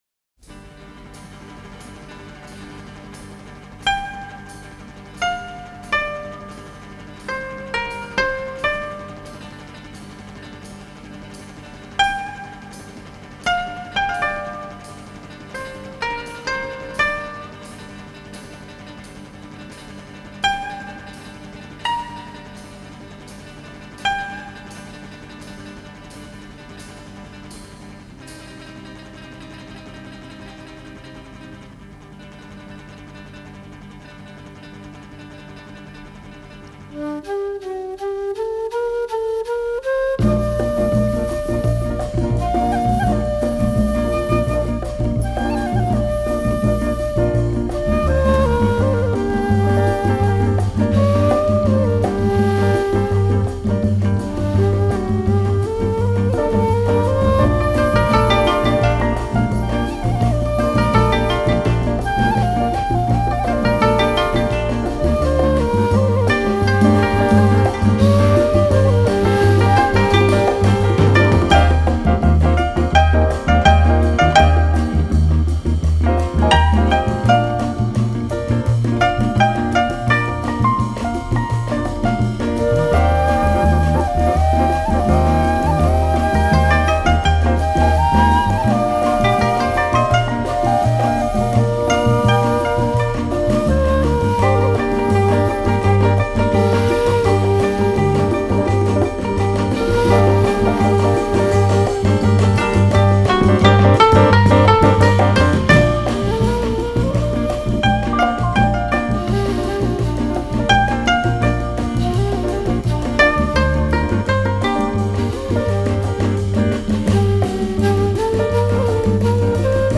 но опять же именно в инструментальном исполнении